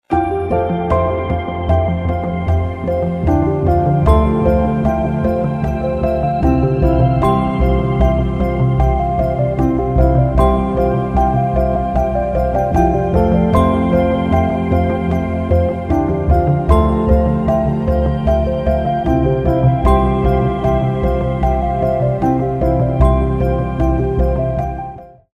رینگتون احساسی و زیبای
برداشتی آزاد از موسیقی های بی کلام خارجی